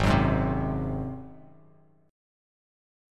Ab7 Chord
Listen to Ab7 strummed